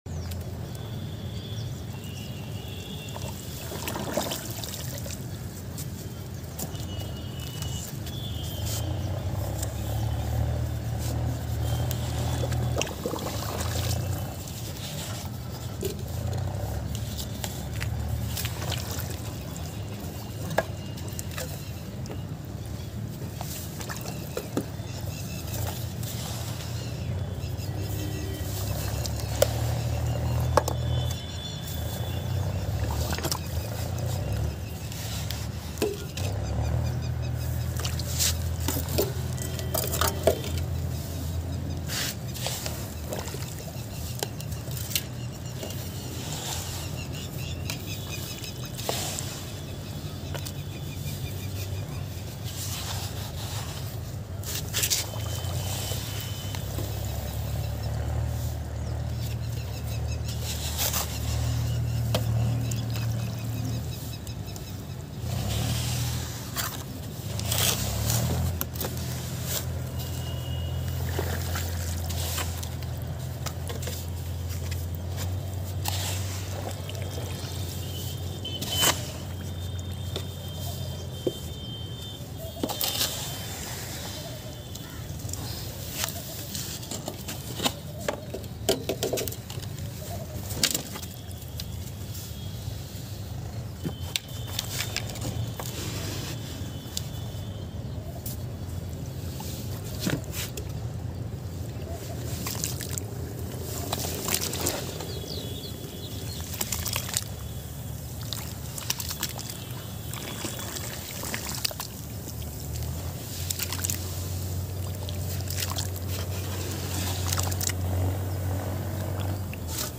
Upload By Everything ASMR